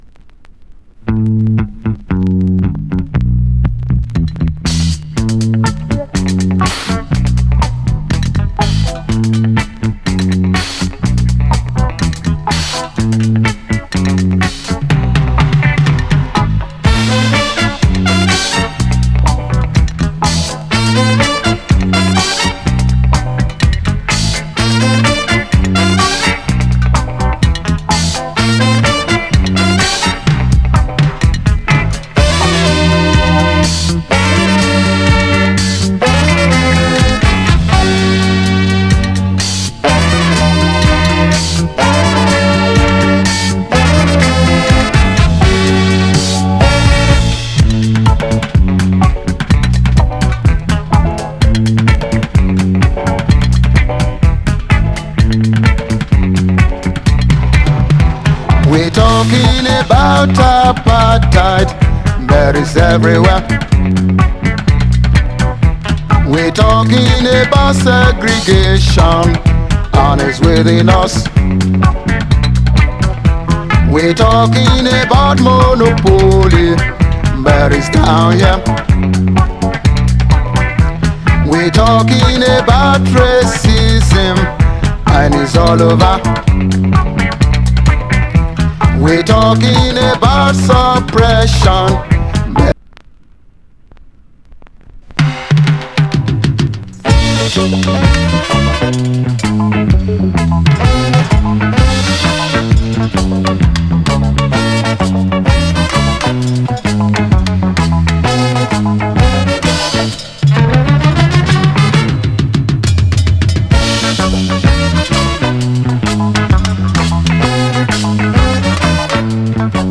Genre:            Funk / Soul
Style:              Afrobeat, Disco